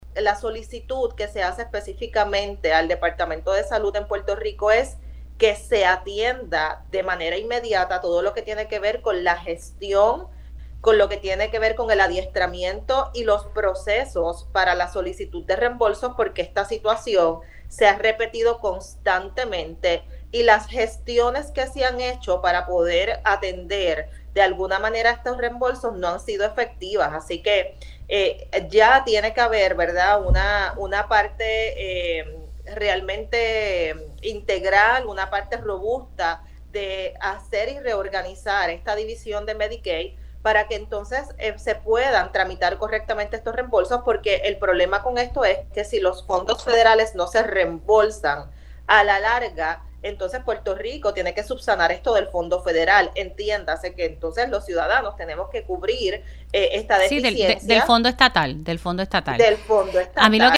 108-IVELISSE-TORRES-INSPECTORA-GENERAL-EXIGE-A-SALUD-GESTIONE-CORRECTAMENTE-EL-REEMBOLSO-DE-FONDOS-FEDERALES.mp3